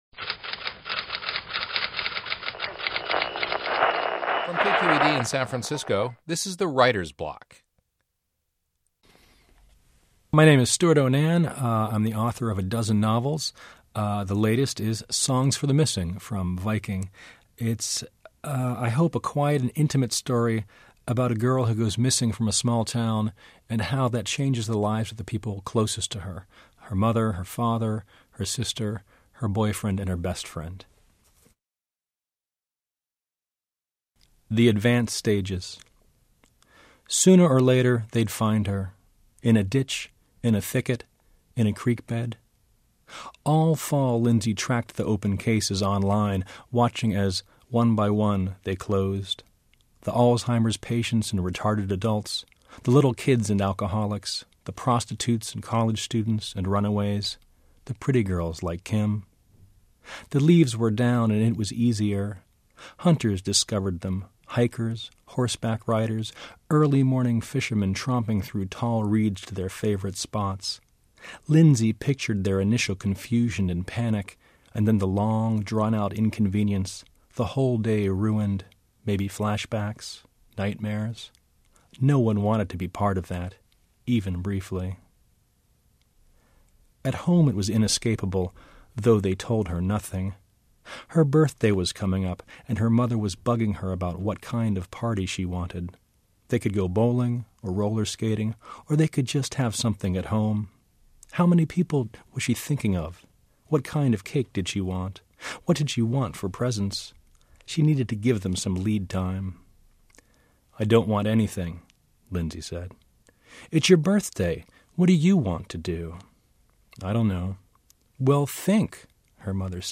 Stewart O’Nan reads a passage from Songs for the Missing, the story of a young girl’s disappearance and its effect on those left behind.